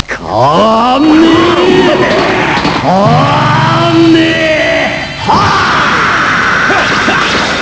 In questa pagina potete trovare i suoni in formato WAV / MP3 dei vari attacchi e delle tecniche speciali dei personaggi.